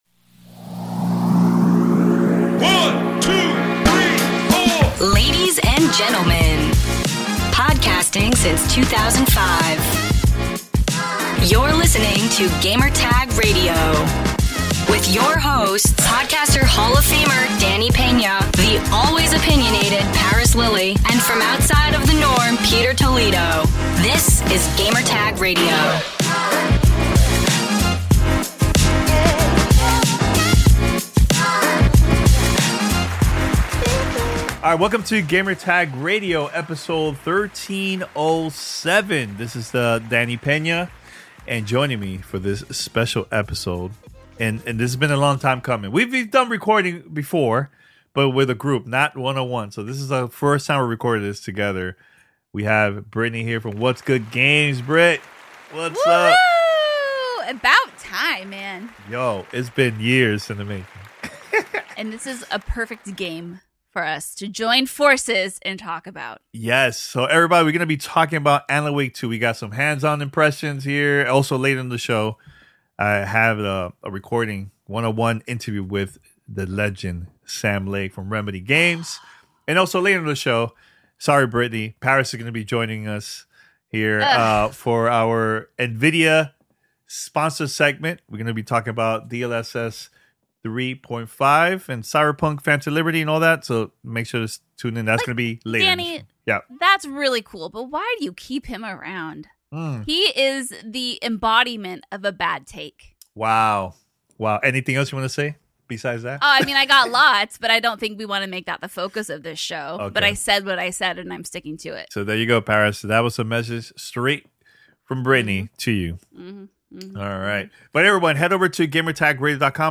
Alan Wake 2 Hands-On Impressions & Sam Lake Interview; NVIDIA DLSS 3.5 Discussion